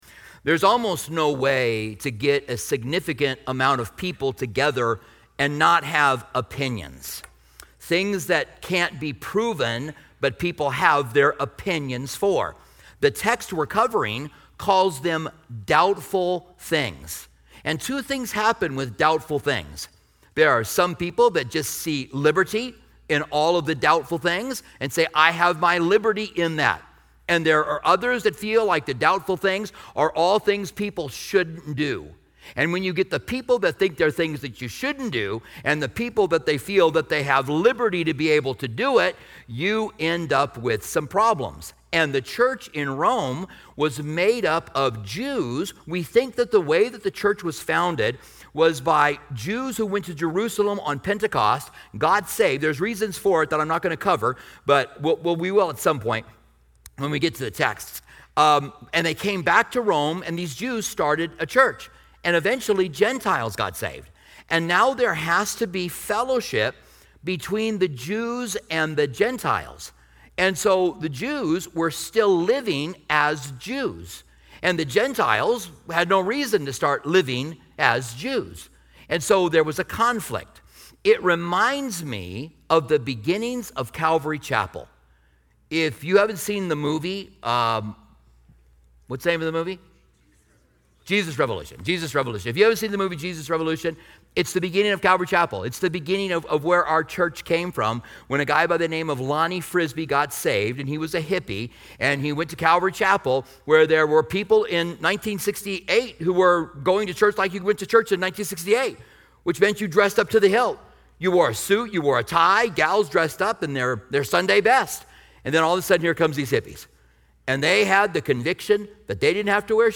The sermon discusses balancing personal convictions and Christian liberty, emphasizing that love and unity should guide our interactions.